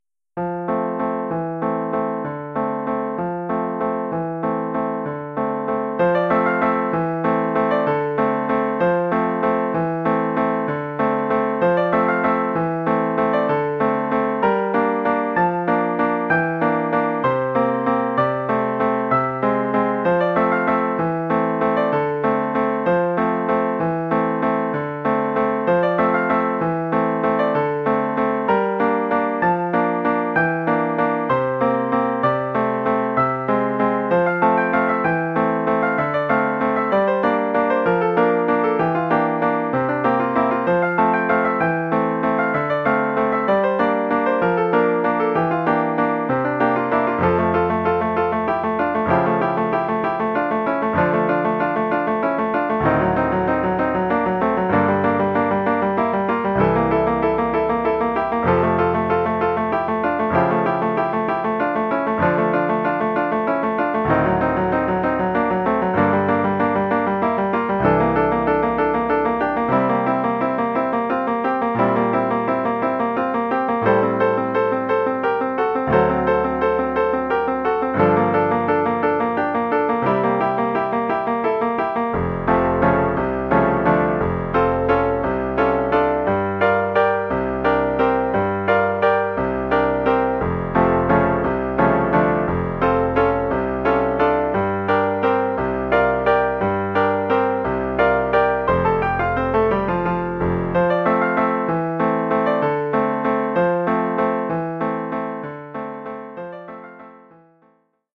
1 titre, piano solo : partie de piano
Oeuvre pour piano solo.